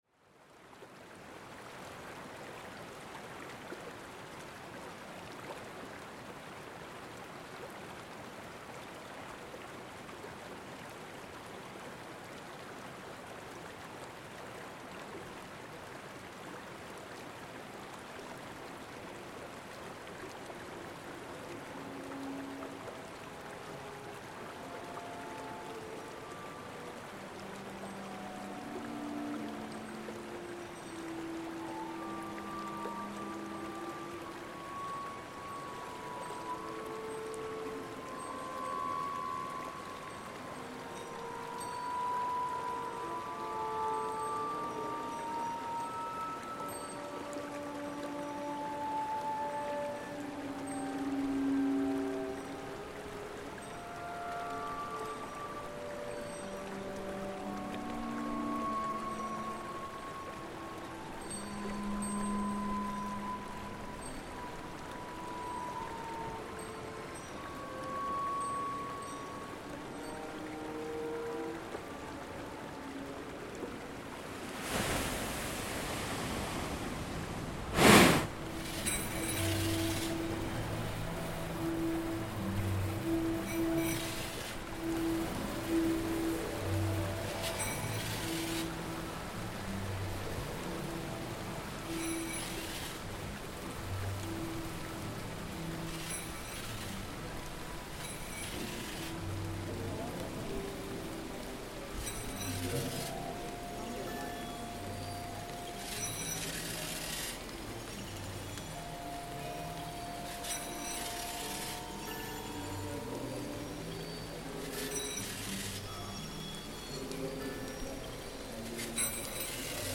Music was performed by Venice biennial sound artists, CA Exploratorium Museum performers, Spain street buskers."